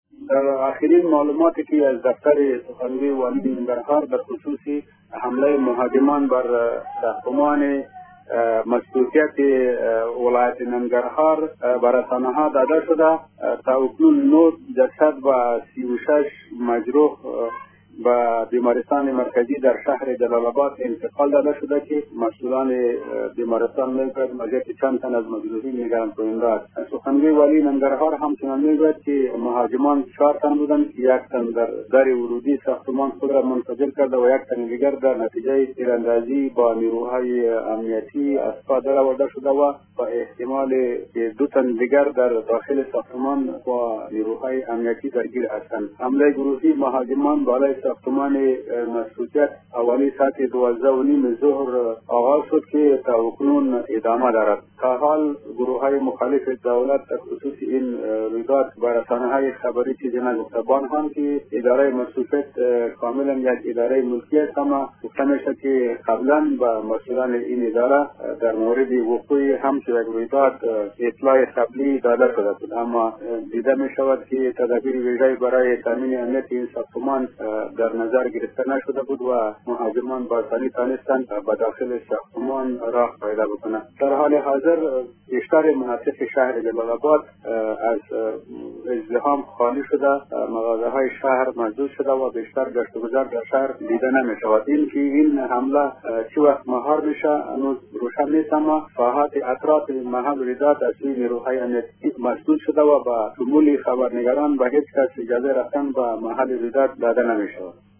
جزئیات این خبر در گزارش